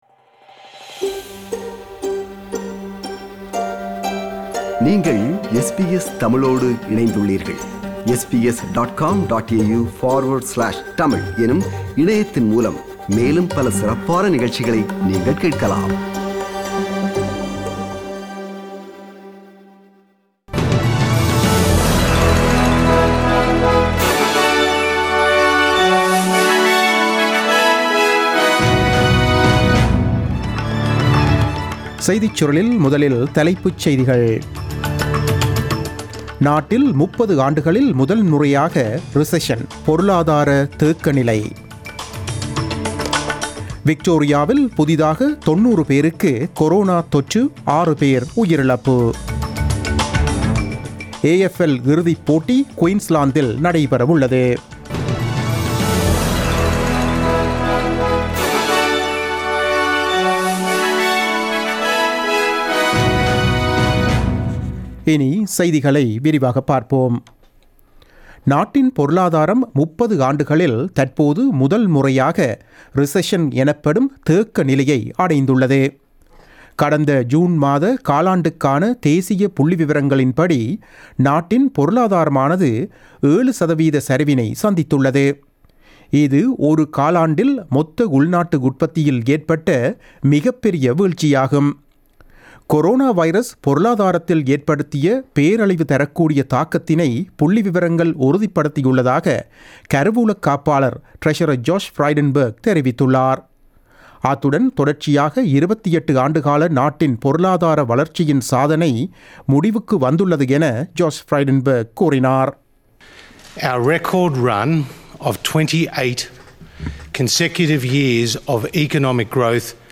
The news bulletin broadcasted on 02 September 2020 at 8pm.